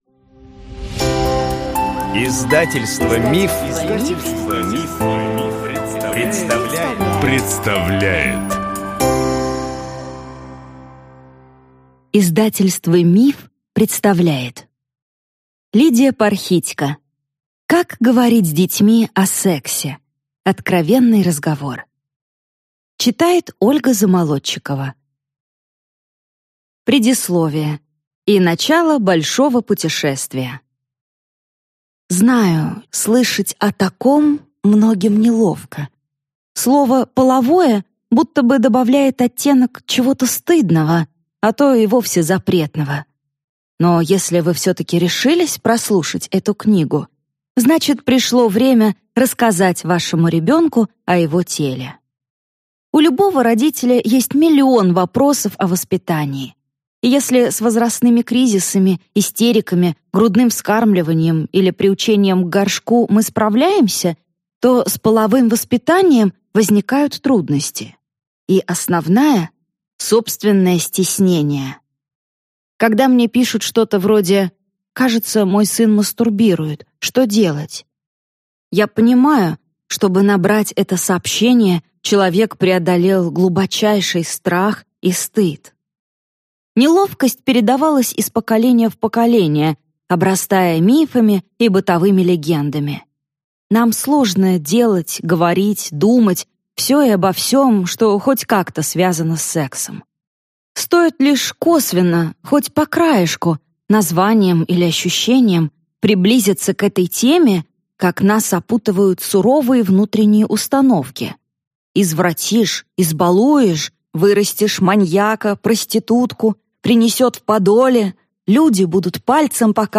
Аудиокнига Как говорить с детьми о сексе | Библиотека аудиокниг